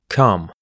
come is kʌm. Note that in spelling, these words are similar.
come.mp3